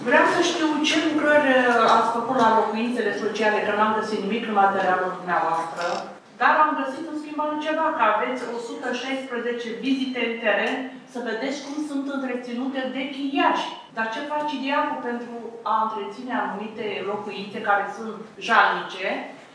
Azi, 27 februarie 2025, Consiliul Local Tulcea s-a reunit într-o nouă ședință ordinară pentru a discuta o serie de proiecte ce vizează, printre altele, administrarea domeniului public, cofinanțarea serviciilor sociale destinate persoanelor fără adăpost și măsuri fiscale pentru contribuabilii aflați în dificultate.
Tatiana Haliț, consilier ADU, a atras atenția asupra stării precare a unor locuințe sociale și a solicitat clarificări privind inspecțiile făcute de DIAP.